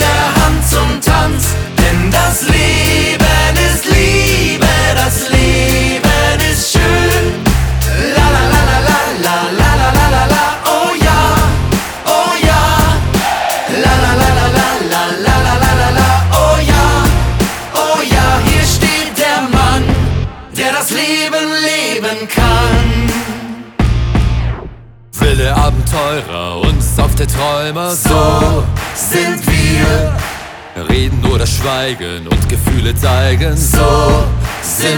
Жанр: Фолк-рок
# German Folk